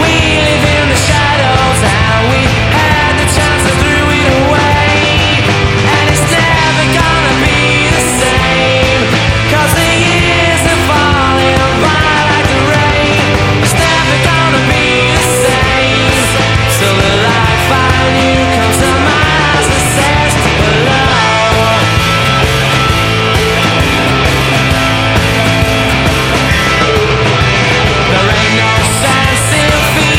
"frPreferredTerm" => "Pop"